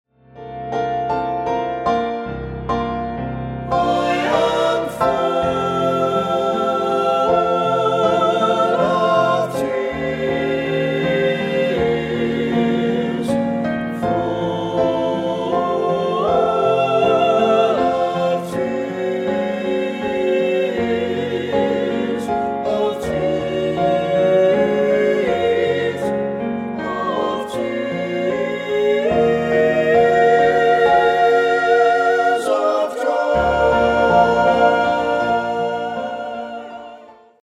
A full-throated and elated celebration of the human spirit.